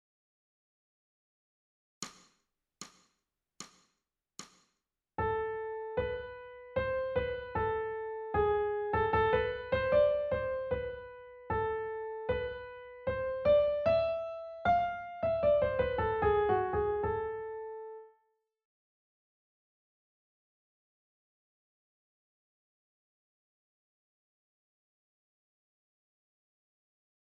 ソルフェージュ 聴音: 1-iii-04